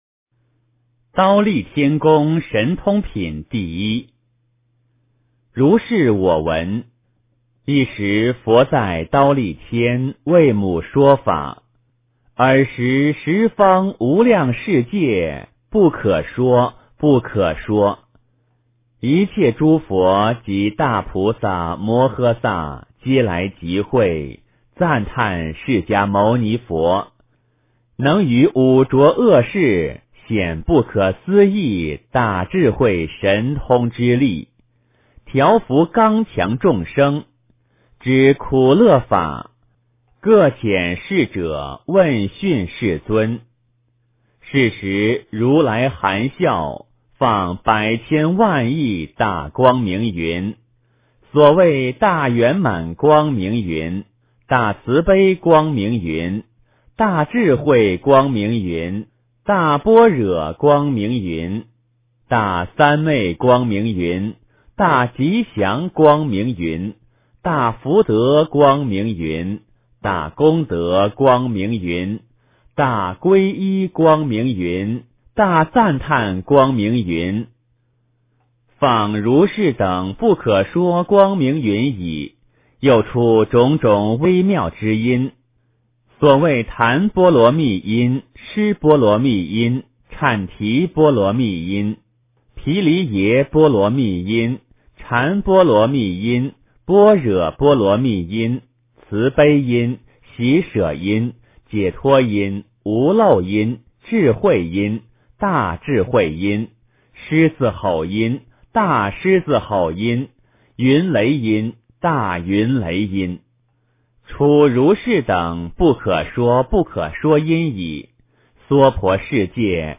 地藏经-忉利天宮神通品第一 - 诵经 - 云佛论坛